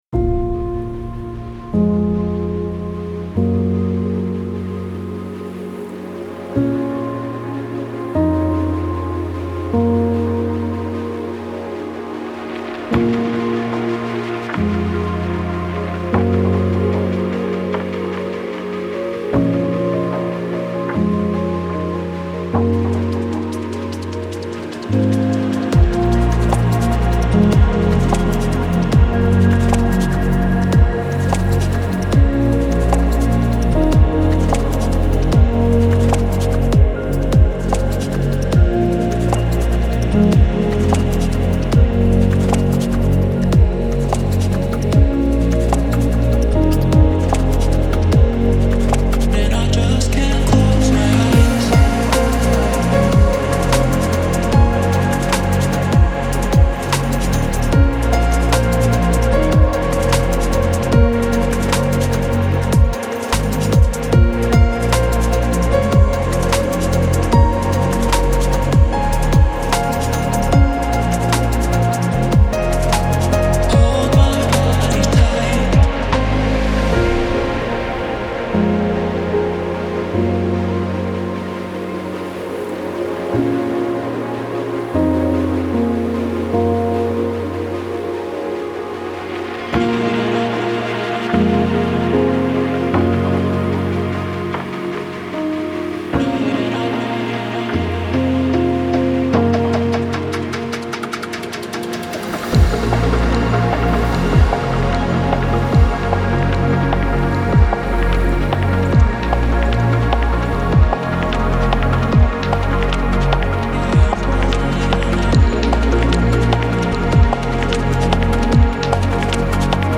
ریتمیک آرام , موسیقی بی کلام
موسیقی بی کلام چیل استپ